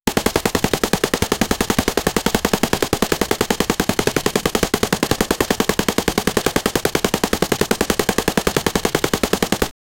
loopable-rapid-fire-sound-rdf5gz6h.wav